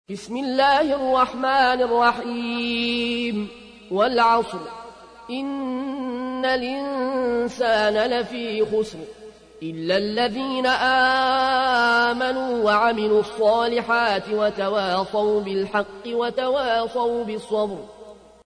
تحميل : 103. سورة العصر / القارئ العيون الكوشي / القرآن الكريم / موقع يا حسين